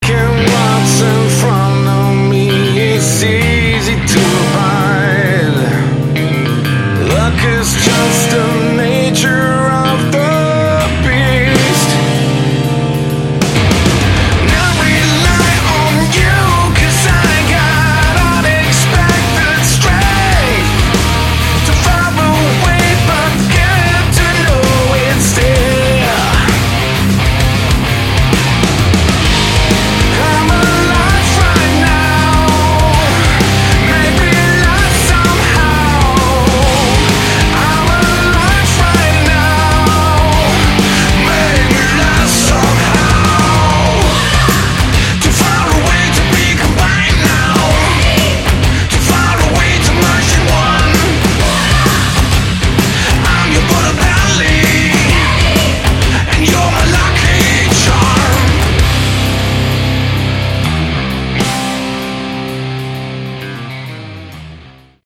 Category: Hard Rock
vocals
bass
drums
guitars